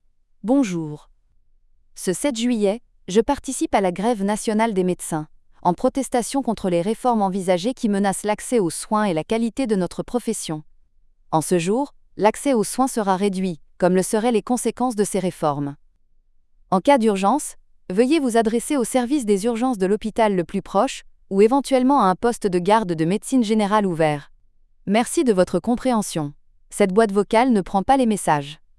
Messageries téléphoniques types à apposer sur votre répondeur :